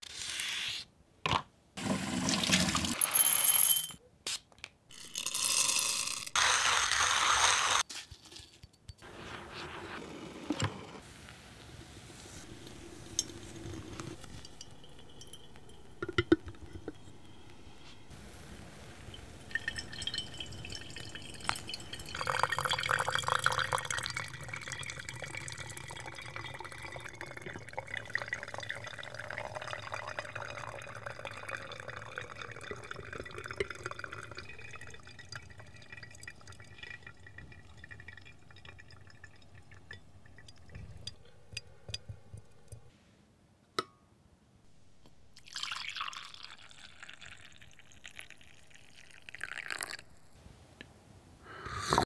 Sounds Of Coffee: The V60 sound effects free download
Sounds Of Coffee: The V60 (ASMR)